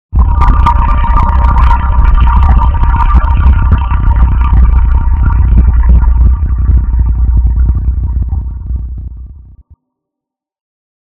ambient / cave
cave1.ogg